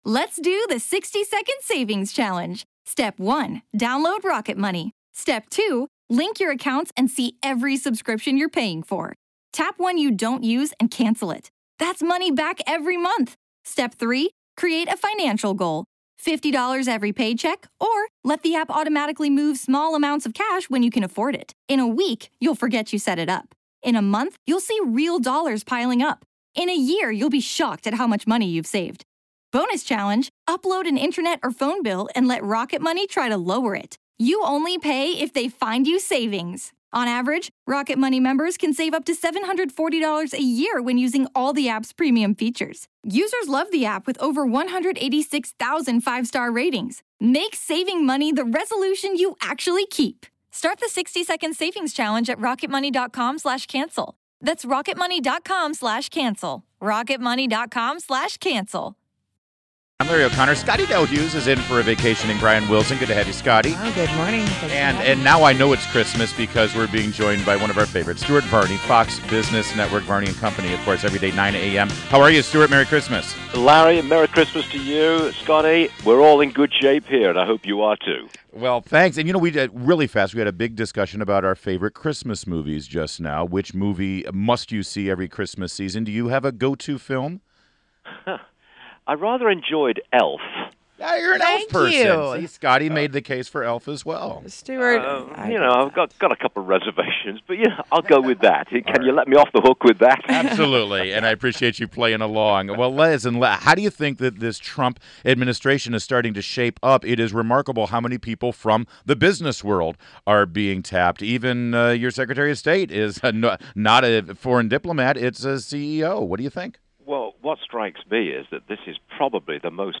INTERVIEW — STUART VARNEY – Anchor of Varney and Company, Fox Business Network – discussed Trump’s pro-business cabinet and his pick of Carl Icahn.